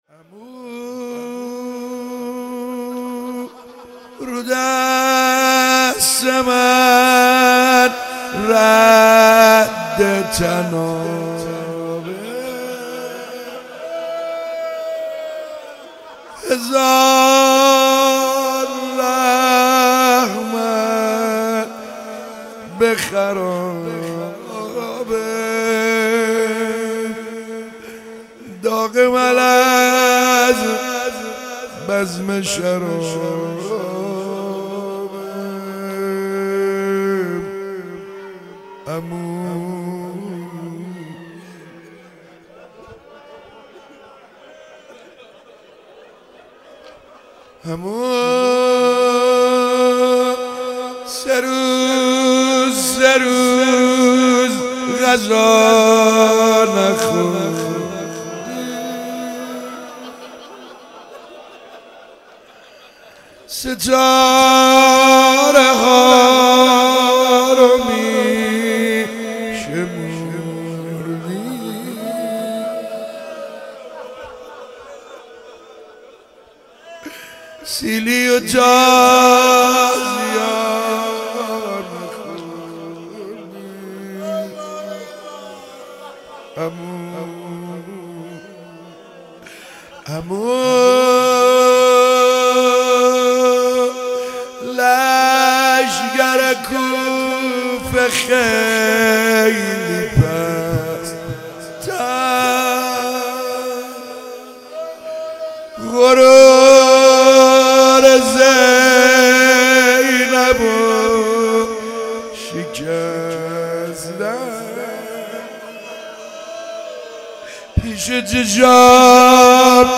شب 2 فاطمیه 95 - روضه